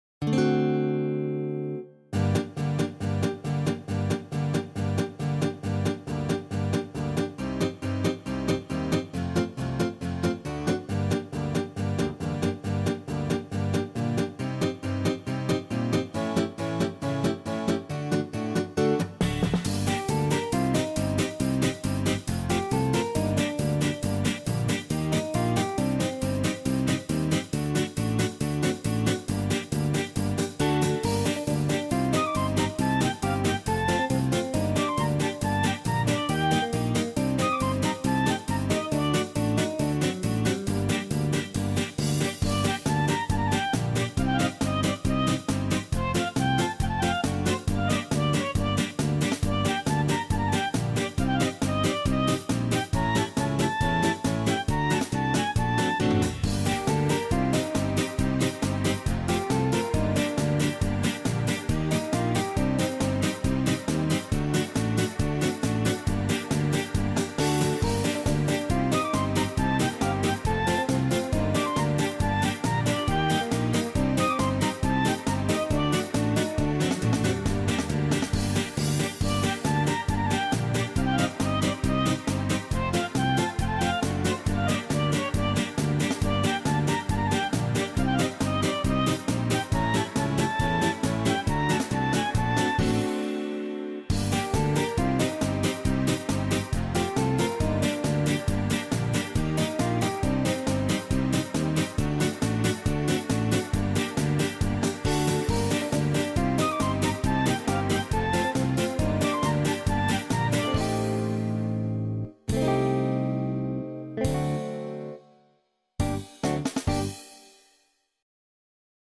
ГлавнаяПесниПесни из детских фильмов